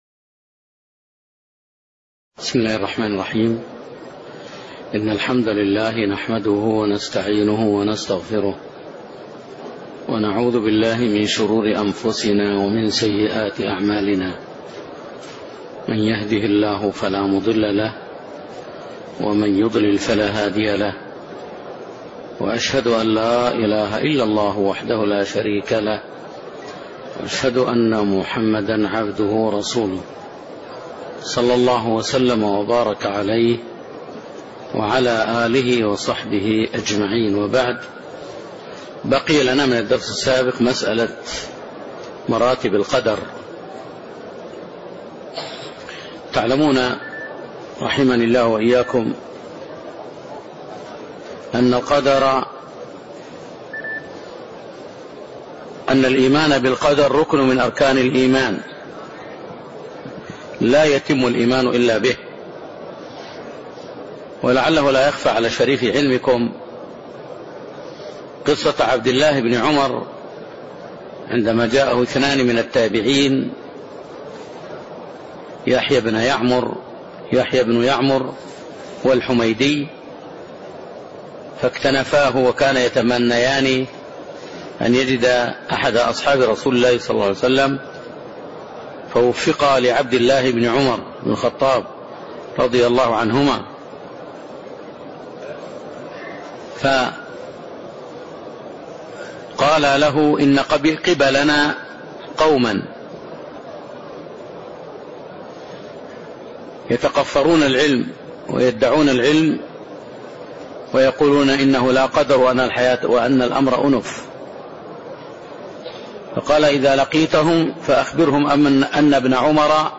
المكان: المسجد النبوي